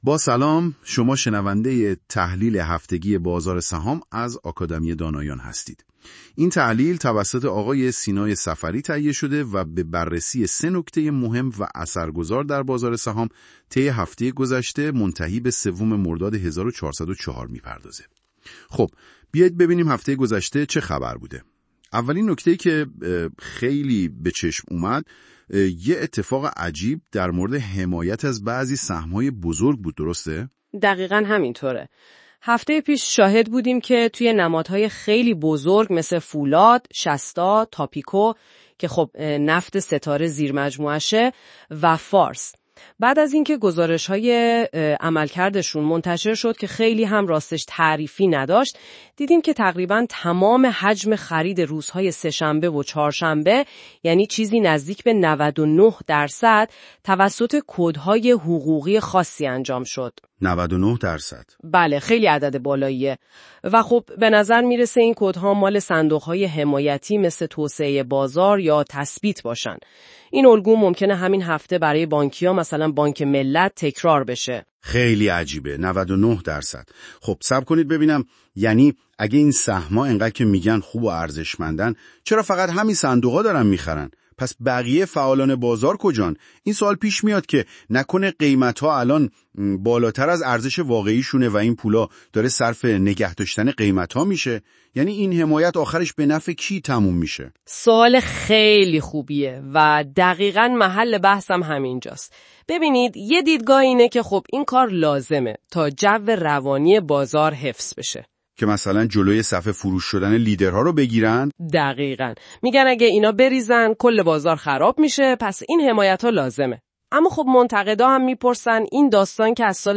آکادمی دانایان، بازار سهام را به صورت هفتگی تحلیل می کند و مهمترین تحولات را به صورت پادکست صوتی با استفاده از هوش مصنوعی مورد بررسی قرار می دهد.